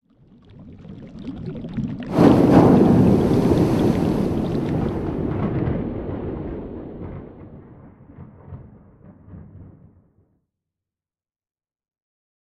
cauldron-done.ogg.mp3